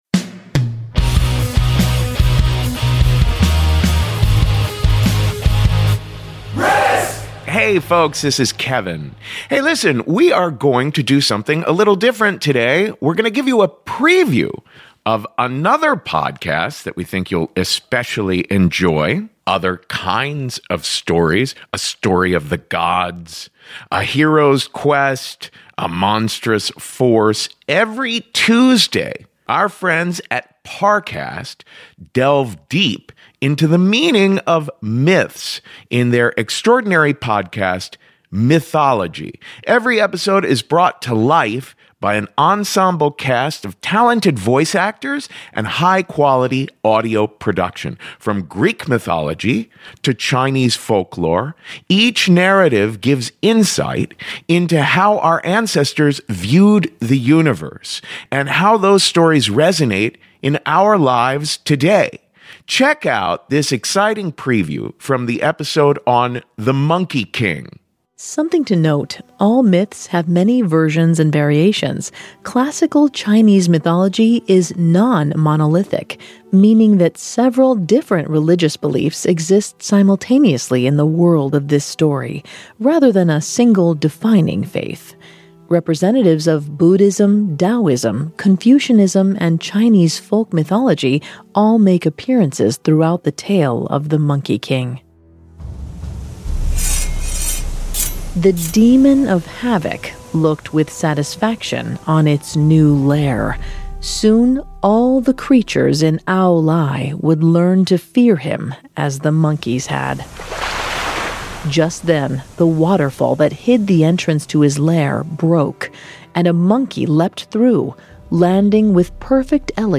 This episodic audio drama brings ancient myths to life for the modern audience. Each episode presents exciting stories and analysis of each myth's history and origins, giving insight into how our ancestors saw the universe.